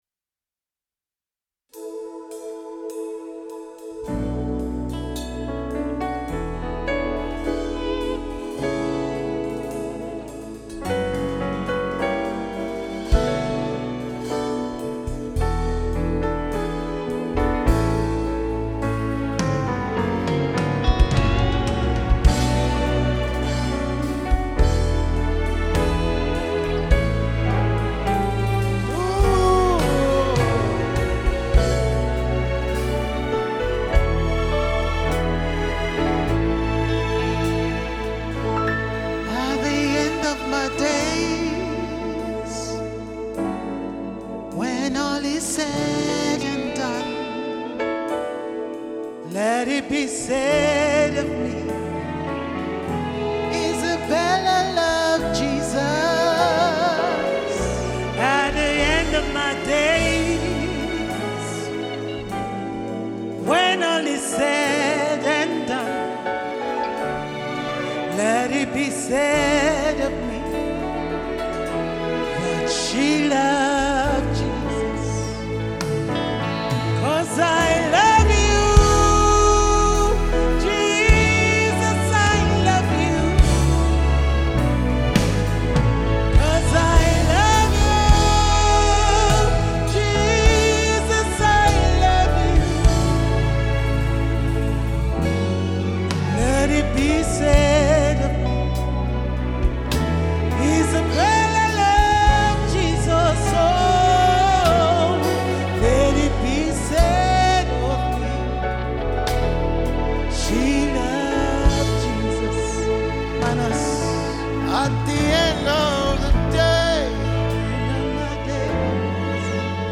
Live recording.
Recorded live in Abuja